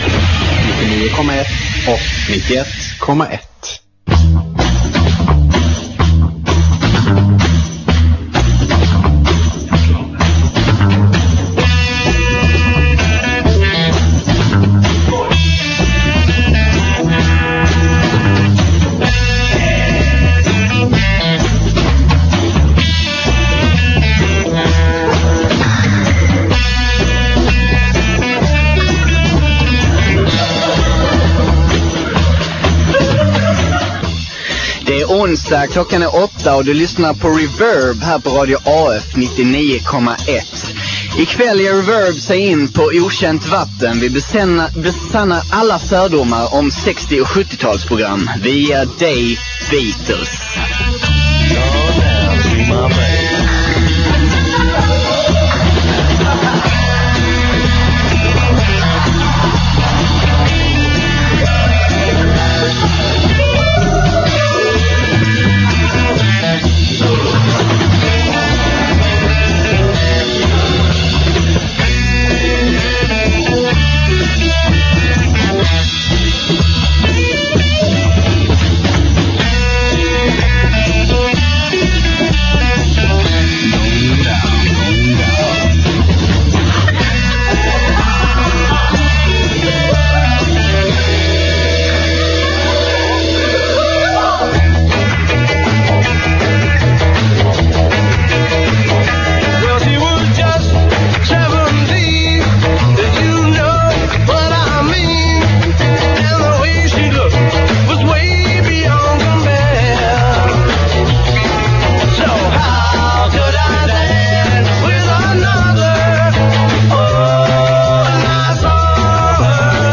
I dagens program spelar vi våra favoritlåtar med bandet, fuskar fram våra favoritanekdoter om bandmedlemmarna och gör vår favoritanalys av orsaken till musikhistoriens stora såväl som små milstolpar: droger.